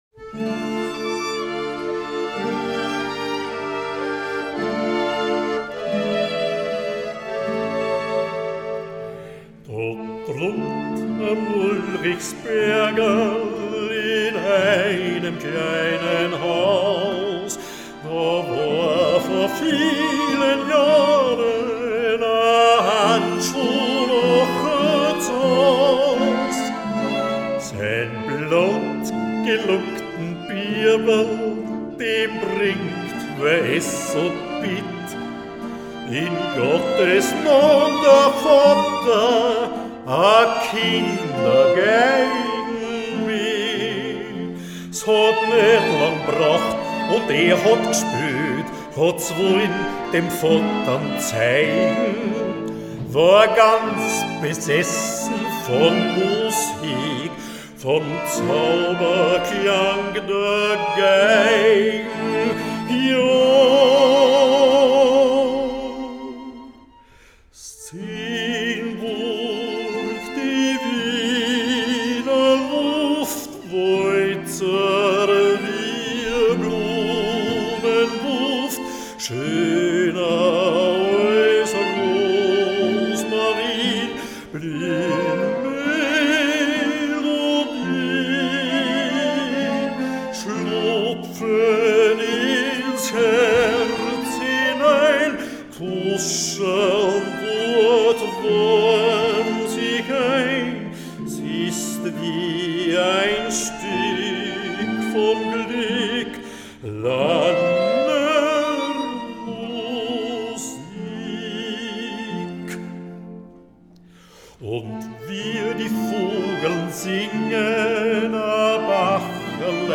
singt und spielt